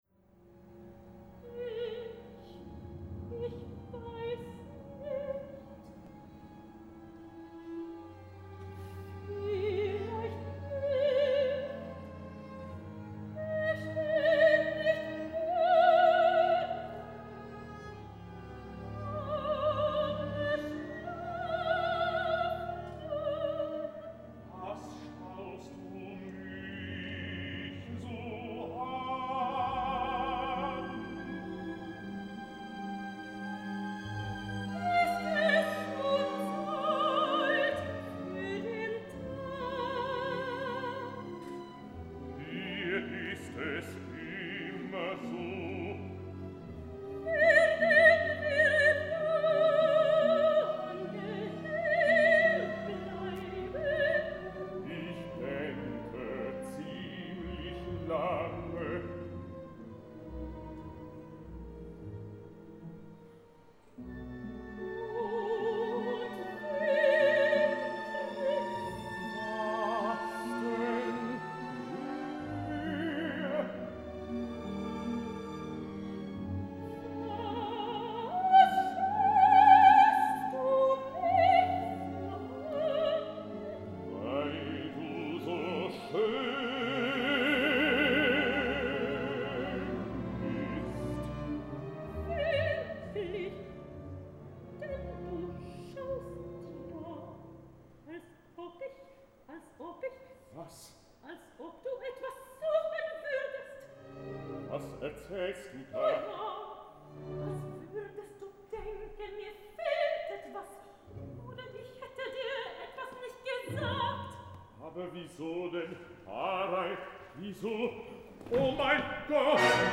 Juli 2012 im Bregenzer Festspielhaus seine Welturaufführung.